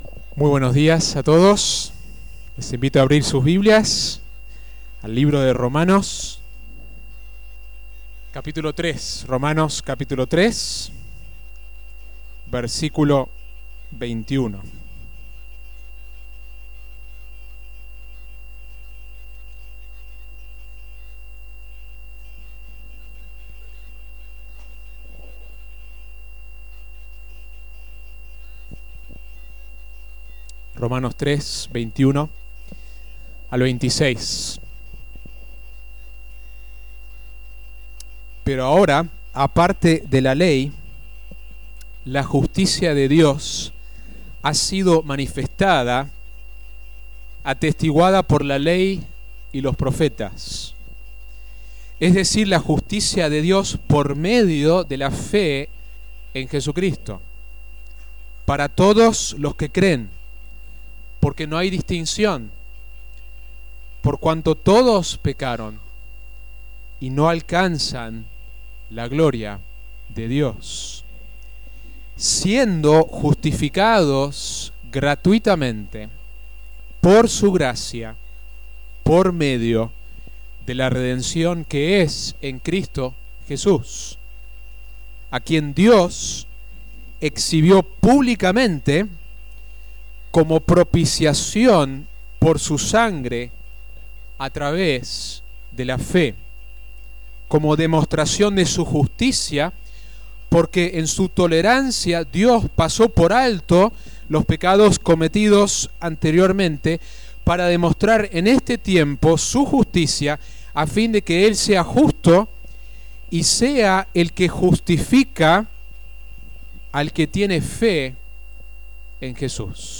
2017 Sermón Sola Fide